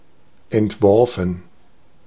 Ääntäminen
Synonyymit aim device dynamic storyboard Ääntäminen US UK : IPA : /dɪˈzaɪn/ US : IPA : /dəˈzɑɪn/ Haettu sana löytyi näillä lähdekielillä: englanti Käännös Ääninäyte Substantiivit 1.